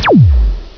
laser.wav